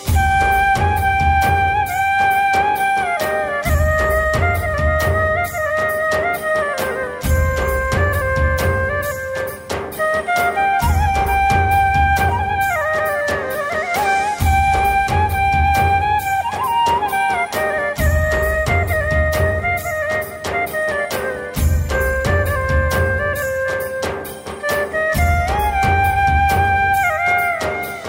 Category: Sad Ringtones